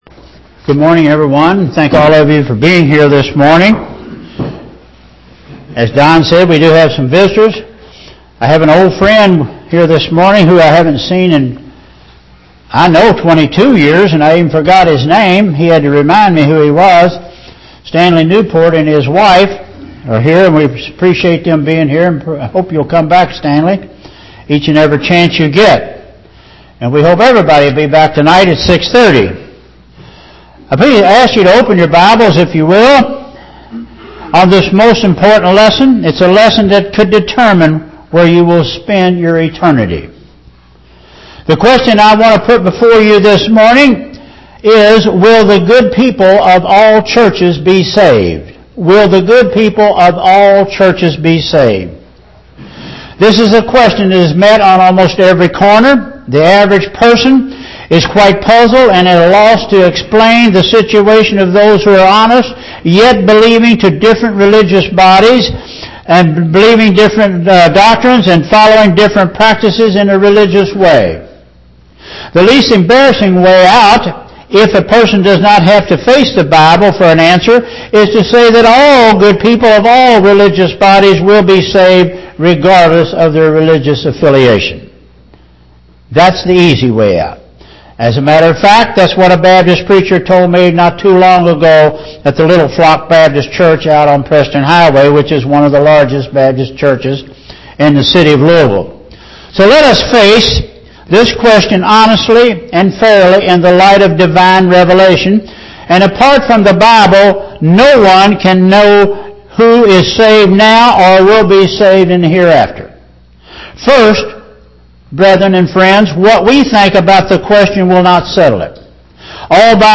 Audio Sermons 2013